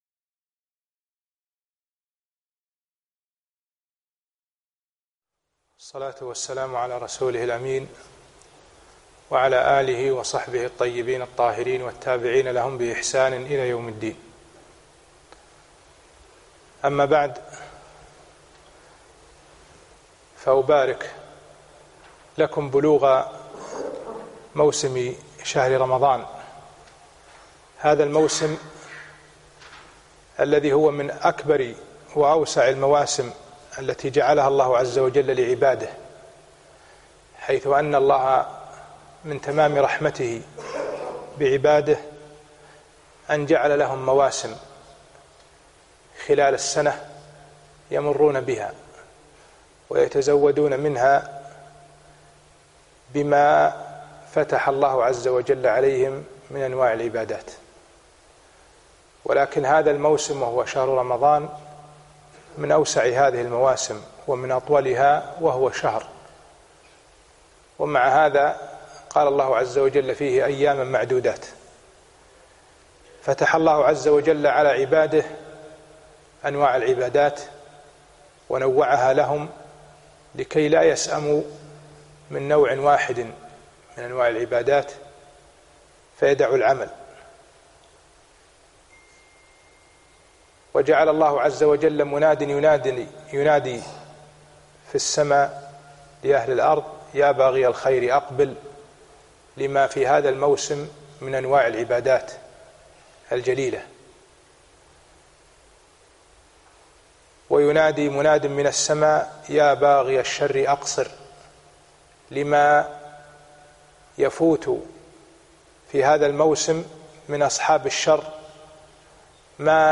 الجمعة 7 رمضان 1438 الموافق 2 6 2017 في مسجد صباح السالم العارضية